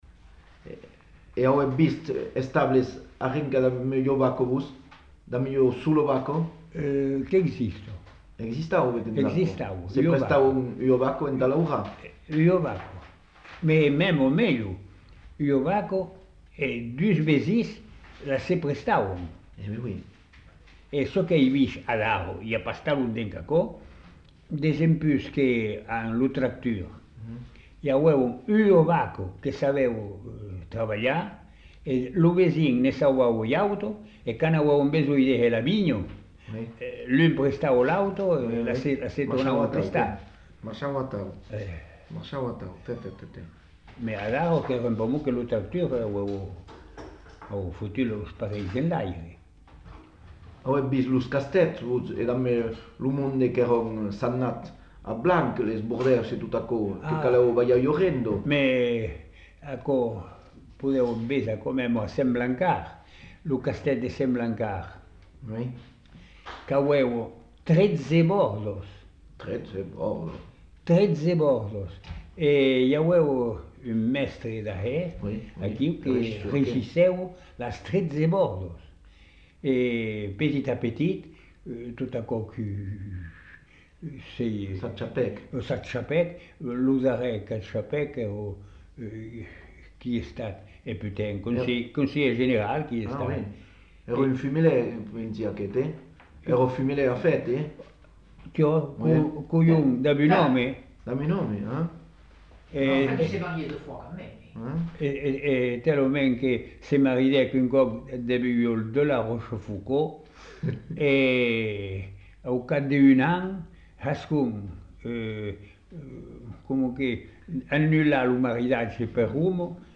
Lieu : Masseube
Genre : témoignage thématique